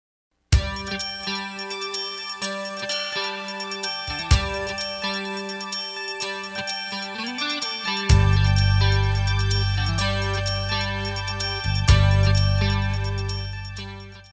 Contemporary 95a